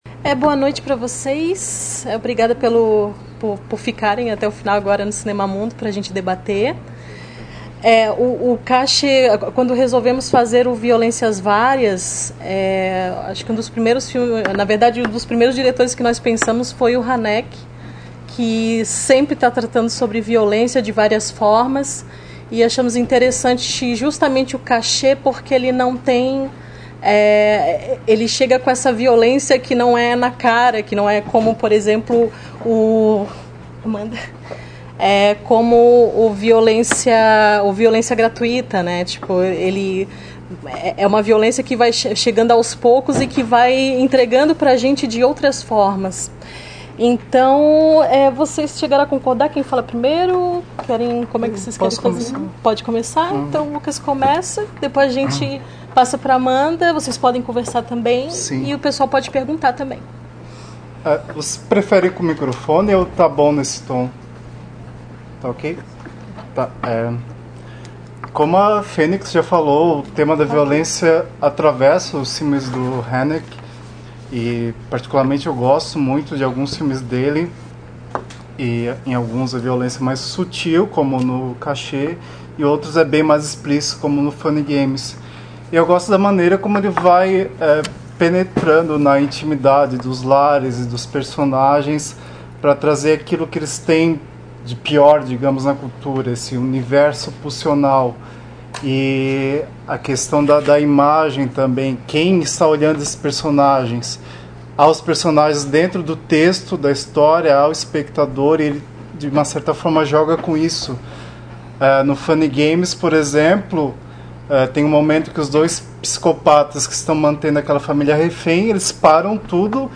Nesta seção você encontrará os áudios dos comentários realizados sobre cada um dos filmes apresentados nas edições do Projeto Cinema Mundo.